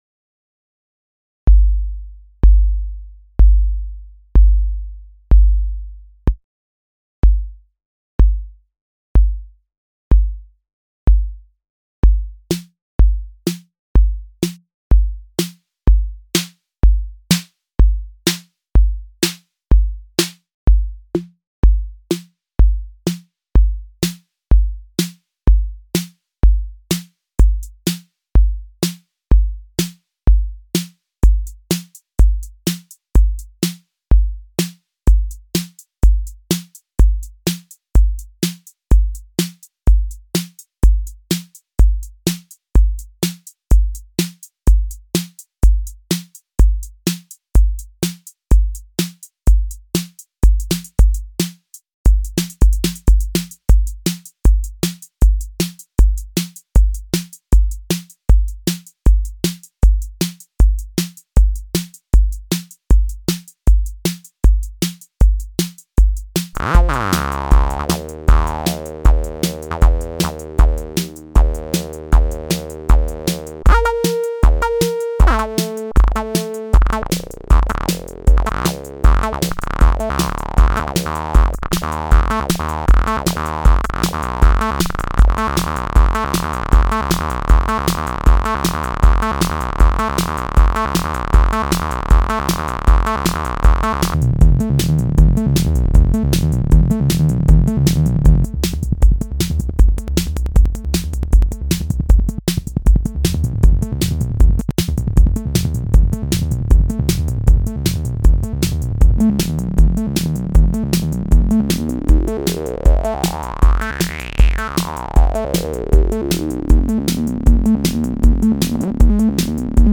Admittedly it's a 303 wannabe, but the moog-style filter makes it shine a bit more.